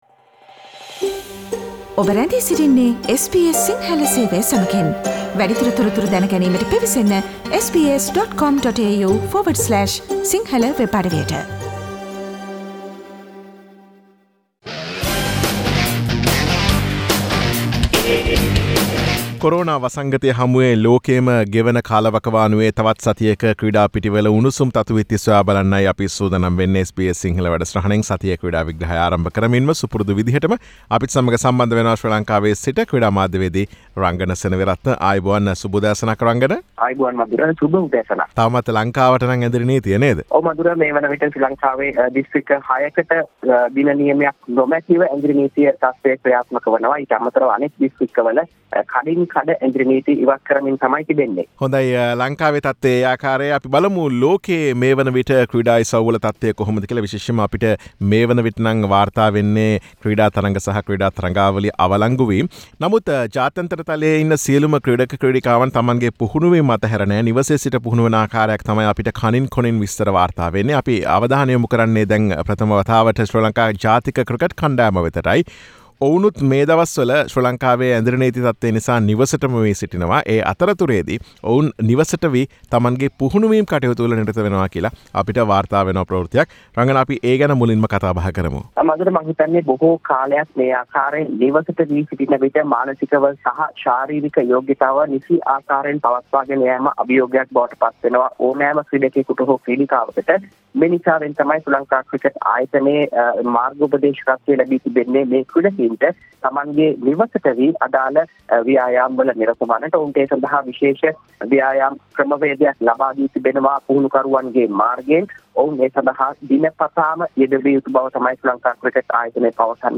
SBS Sinhalese Sports Wrap